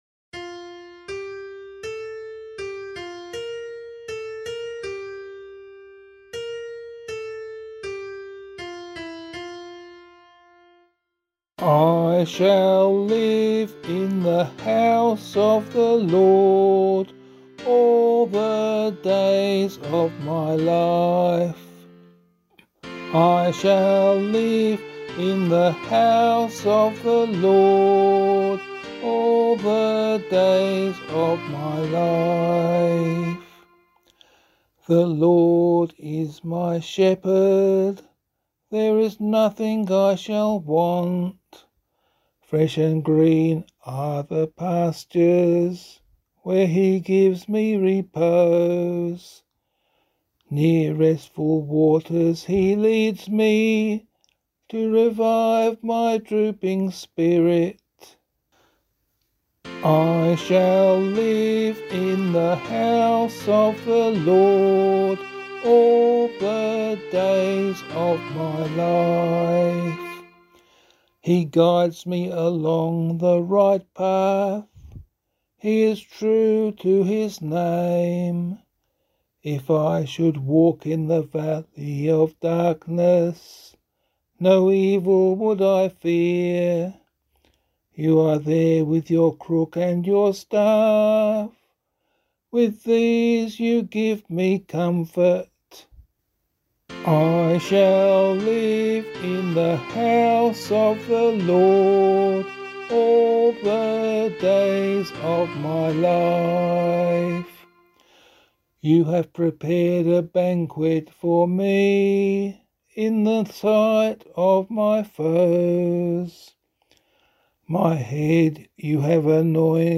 The demo transposes the sheet music from F to D.